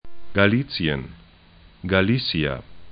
Galicien ga'li:tsĭən Galicia ga'li:sĭa es/ gl